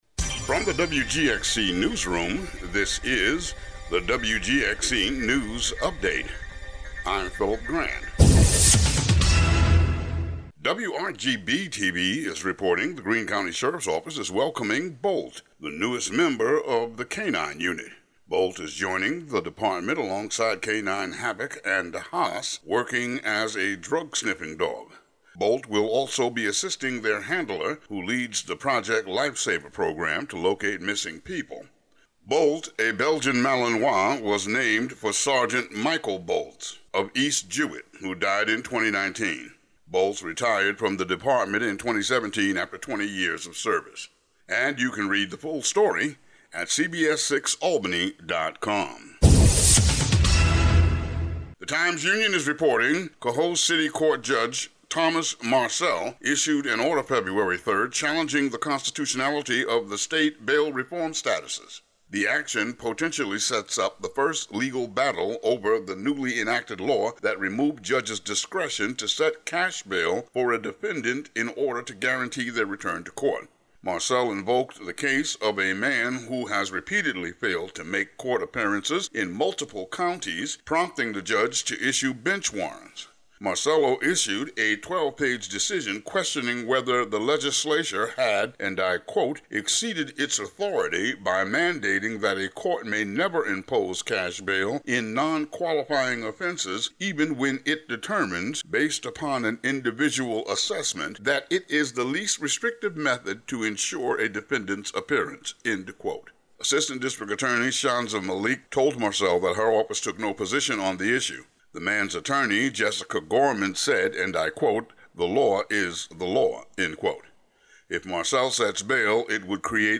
Today's local news update.